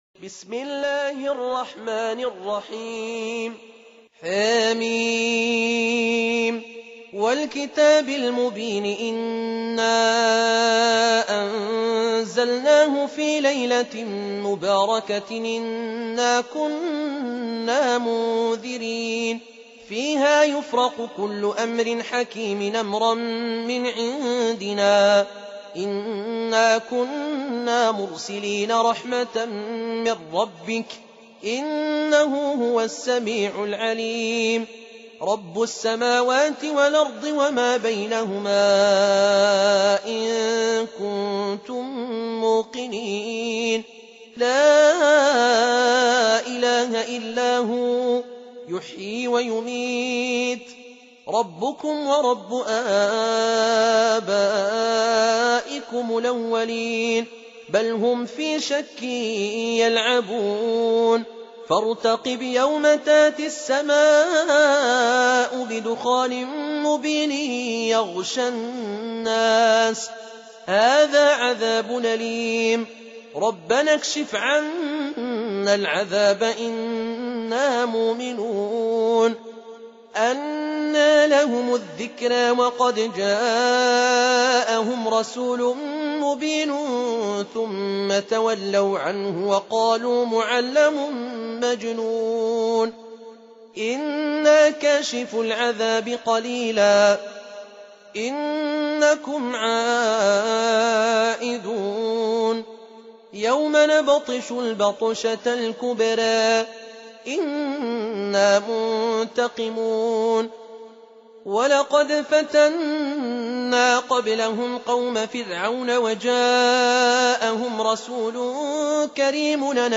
Surah Sequence تتابع السورة Download Surah حمّل السورة Reciting Murattalah Audio for 44. Surah Ad-Dukh�n سورة الدّخان N.B *Surah Includes Al-Basmalah Reciters Sequents تتابع التلاوات Reciters Repeats تكرار التلاوات